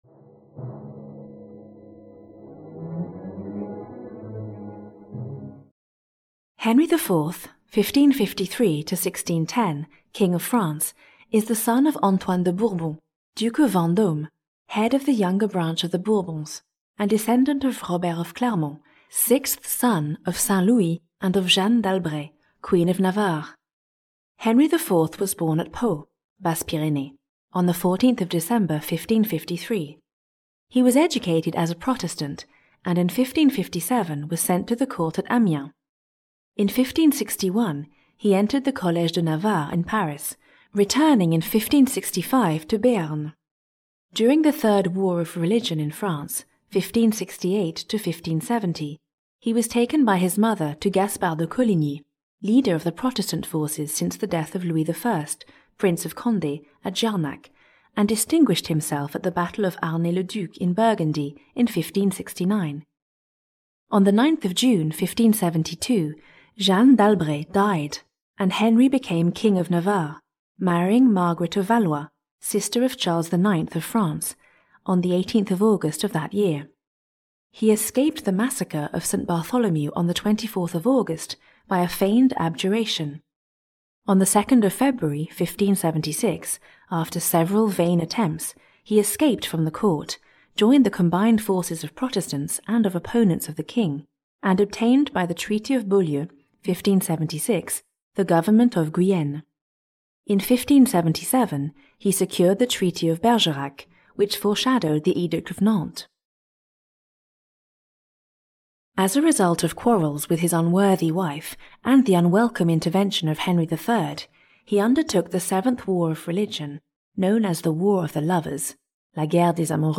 Ukázka z knihy
This collection of audiobooks will help you understand a crucial part of the history of French monarchy. For each King, you will find a detailed description of the style of his reign and his political achievements, narrated in a lively way.